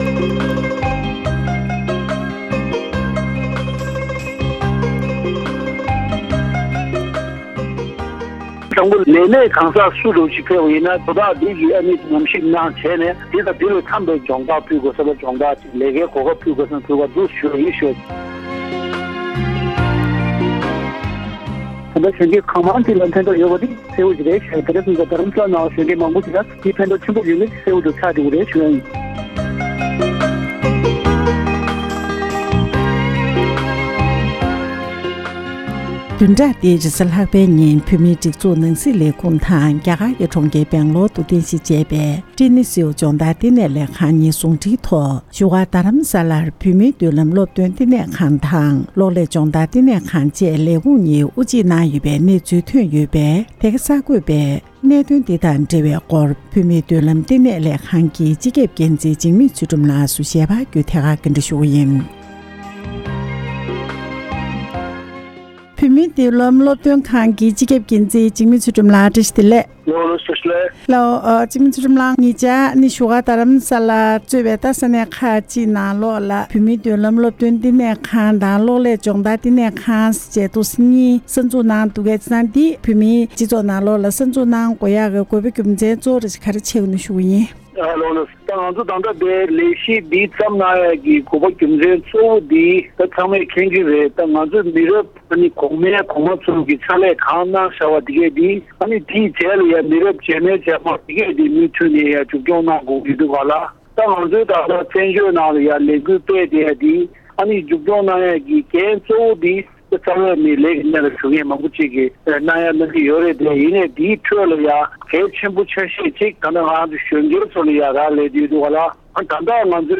འབྲེལ་ཡོད་མི་སྣར་གནས་འདྲི་ཞུས་པ་ཞིག་ལ་གསན་རོགས་ཞུ༎